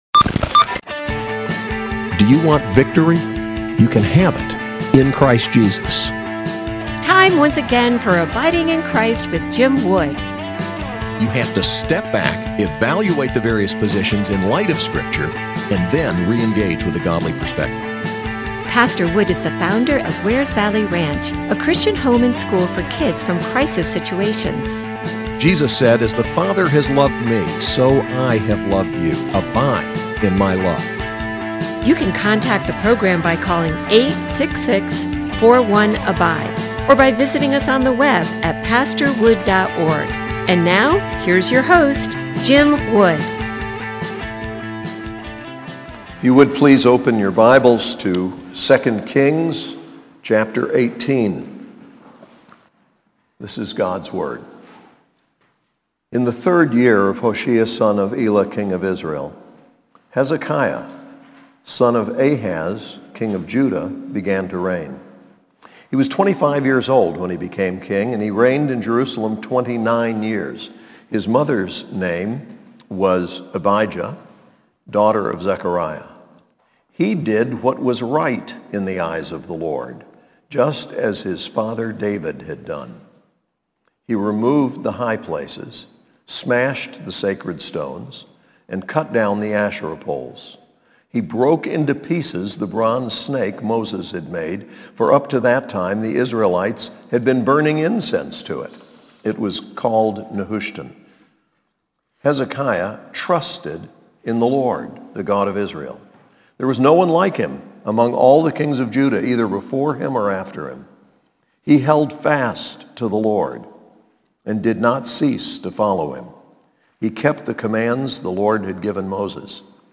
SAS Chapel: 2 Kings 18:1-16